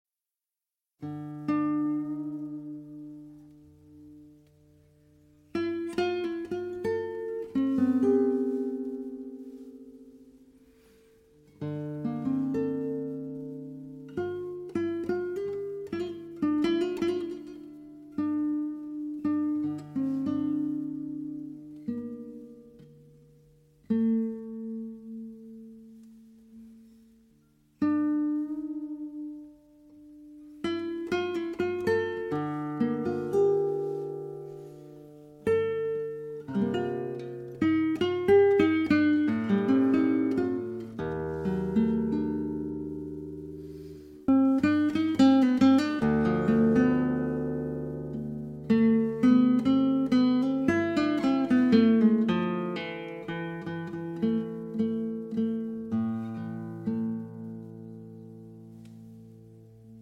Guitar
Oud